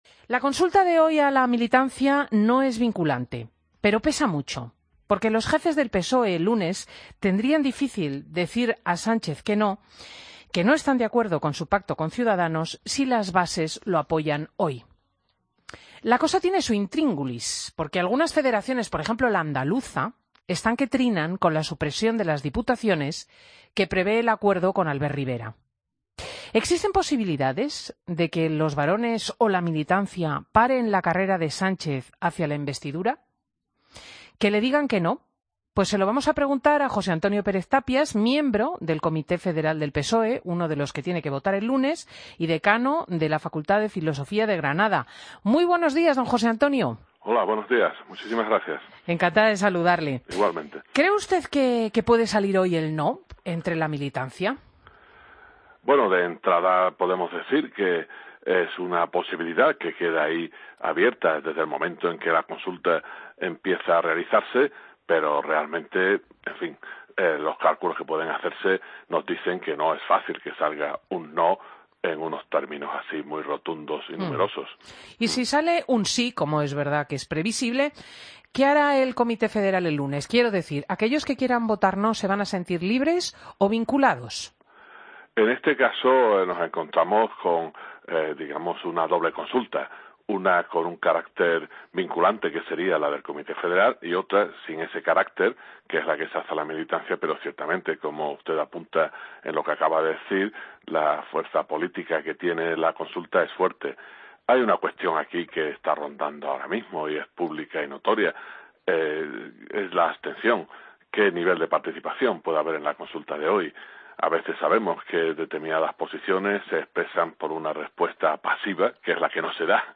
Escucha la entrevista a José Antonio Pérez Tapias, miembro del Comité Federal del PSOE, en Fin de Semana de COPE